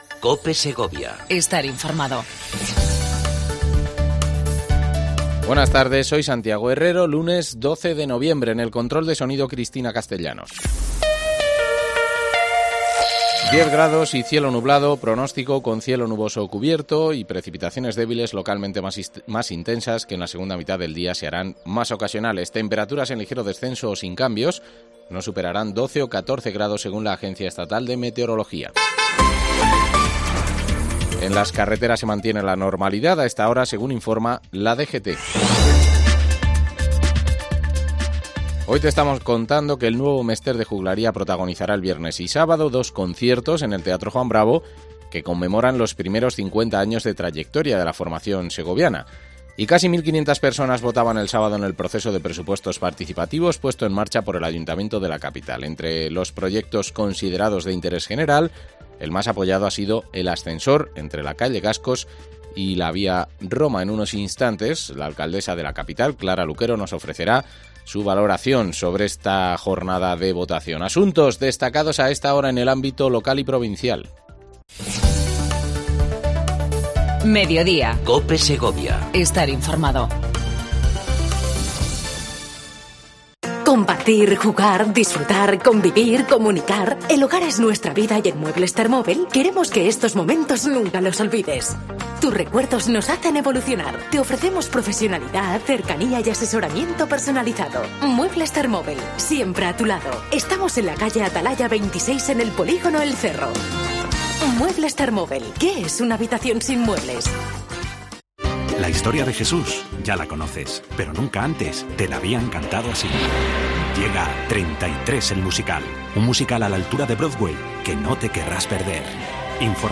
AUDIO: Entrevista mensual a la alcaldesa de la capital segoviana, Clara Luquero